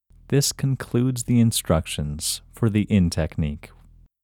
IN – Second Way – English Male 31